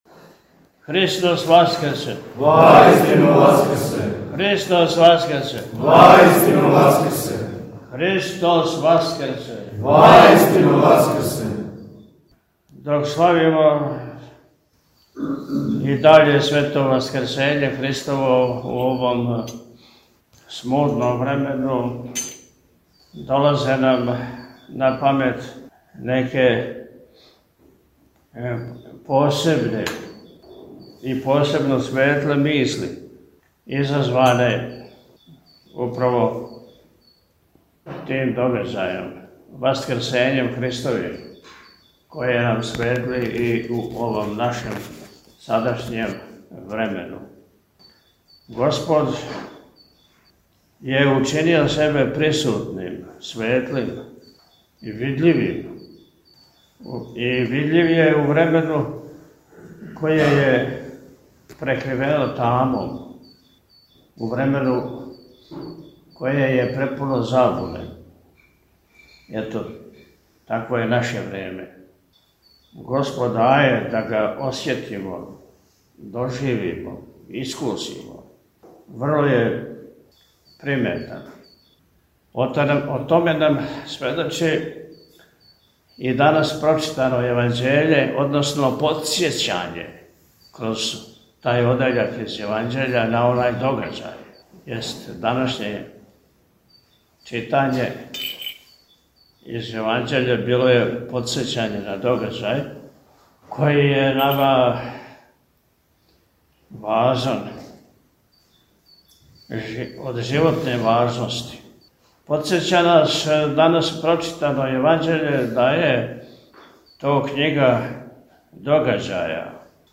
По отпусту, у пастирској беседи, Високопреосвећени је сабраном верном народу, поред осталог, рекао: – Господ је учинио себе присутним, светлим и видљивим, и видљив је у времену које је преривено тамом, у времену које је препуно забуне.
Митрополит-Атанасије-Сељане-Томиндан.mp3